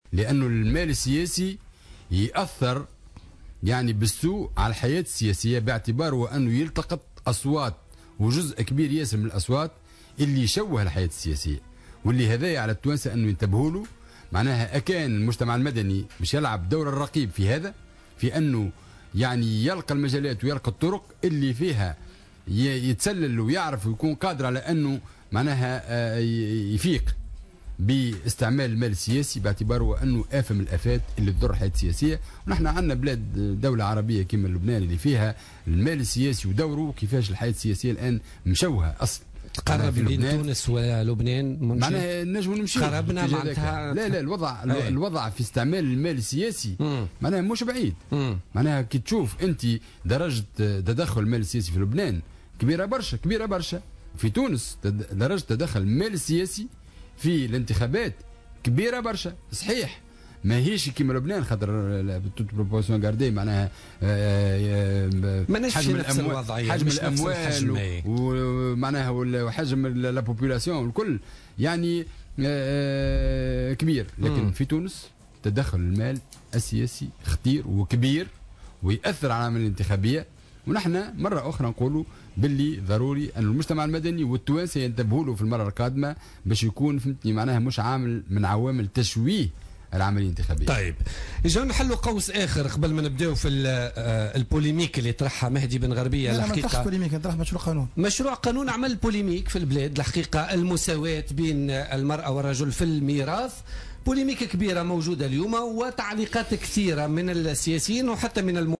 قال النائب عن الجبهة الشعبية المنجي الرحوي ضيف بوليتيكا اليوم الثلاثاء 17 ماي 2016 أن المجتمع المدني يجب أن المال السياسي لعب دور هام في الانتخابات السابقة وهو أمر يجب أن ينتبه له التونسيين في الانتخابات القادمة على حد قوله.